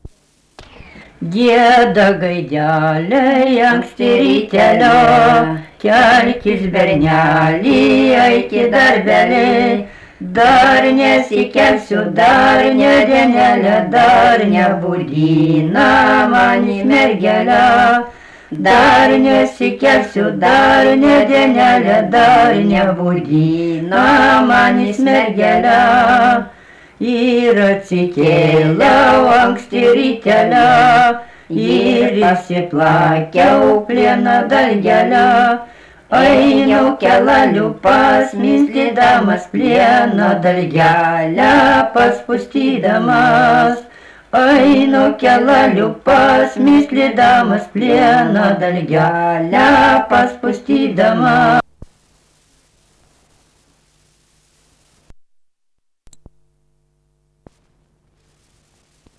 daina
vokalinis